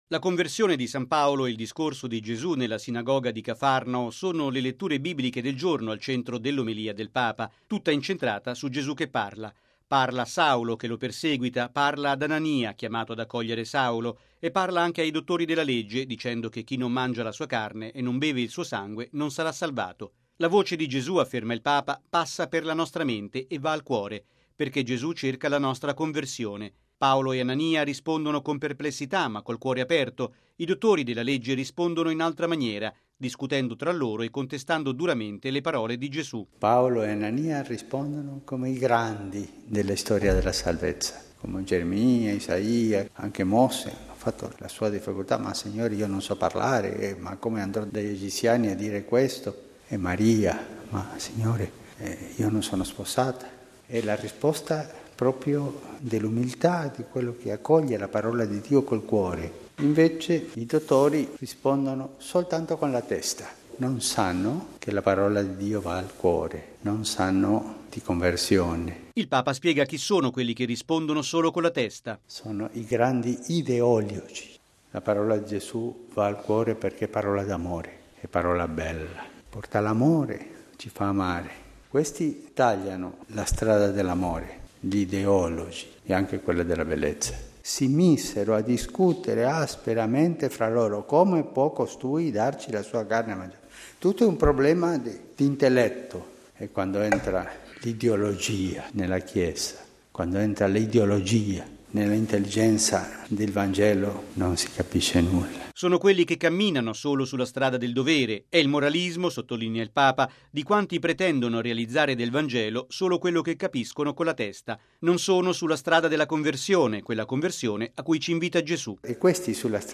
Logo 50Radiogiornale Radio Vaticana